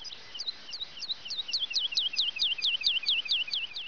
Bird08.wav